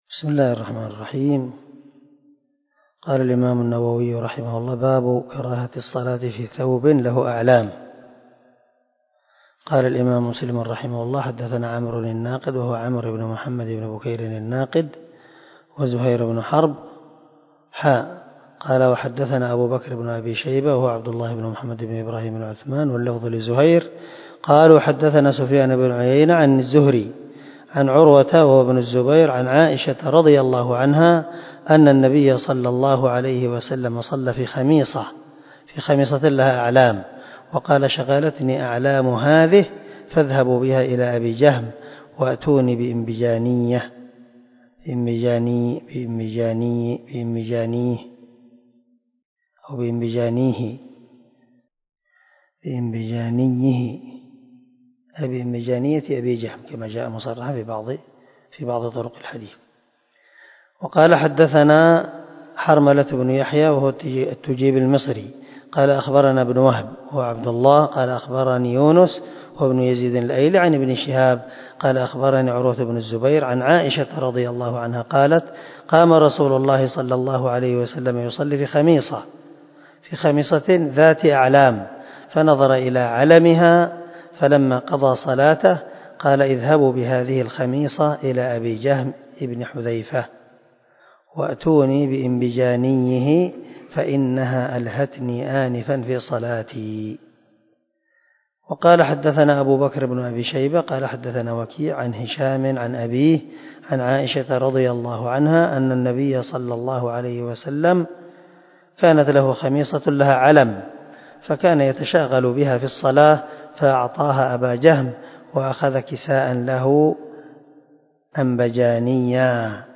349الدرس 21 من شرح كتاب المساجد ومواضع الصلاة حديث رقم ( 556 ) من صحيح مسلم